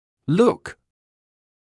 [luk][лук]смотреть; выглядеть; взгляд; внешность, внешний вид